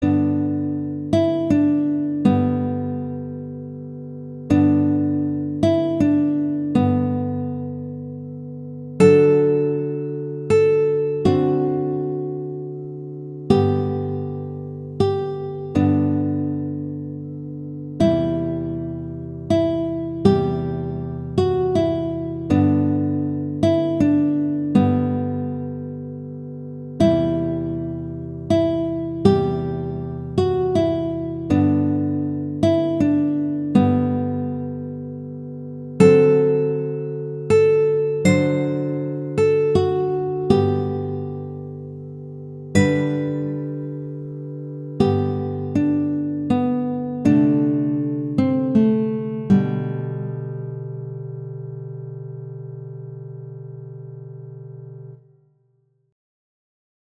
Note Range: E2-C5
G major
♩=80 BPM